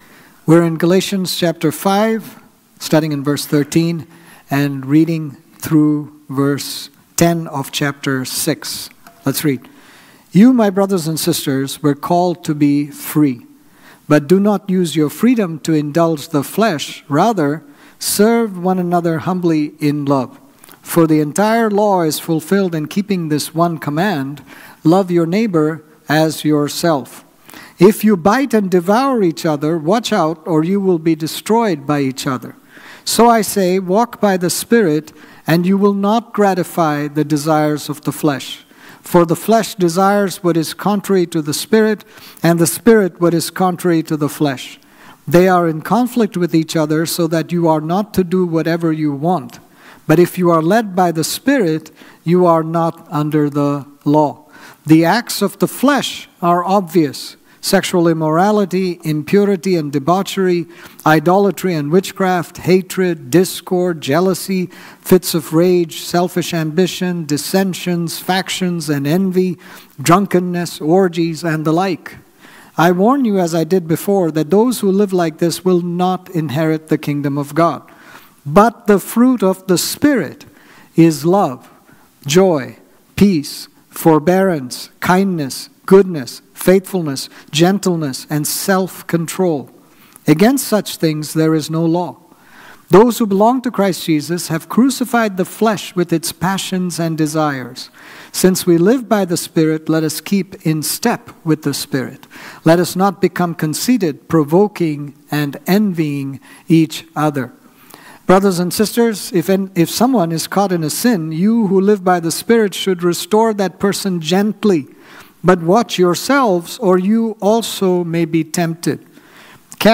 This sermon on Galatians 5:13-6:10 was preached